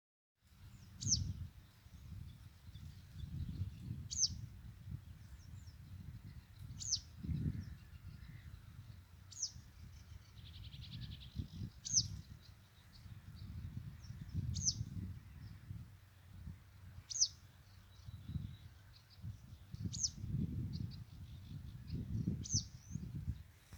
Birds -> Wagtails ->
Citrine Wagtail, Motacilla citreola
StatusSpecies observed in breeding season in possible nesting habitat